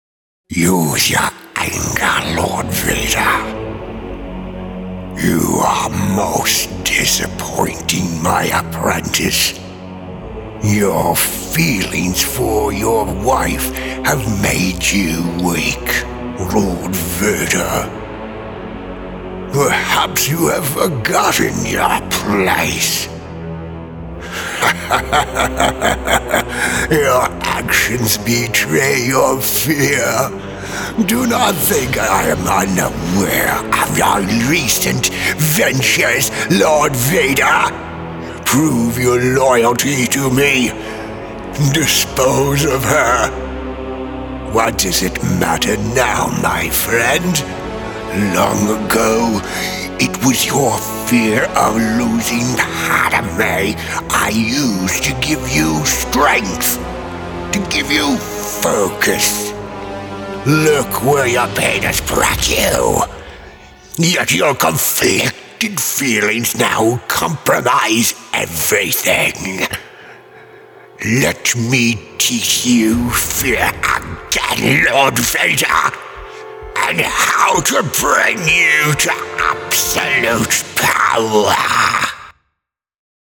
Darth Sidious- Star Wars Audiodrama
Middle Aged